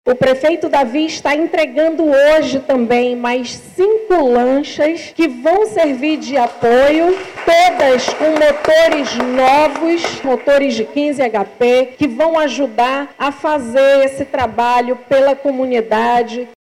A titular da Semsa, Shádia Fraxe, destaca a comunidades que são atendidas pela secretaria e que agora dispõem desses serviços por meio das estruturas revitalizadas.
A secretária, também, ressalta a entrega se lanchas que vão dar apoio aos atendimentos na comunidade.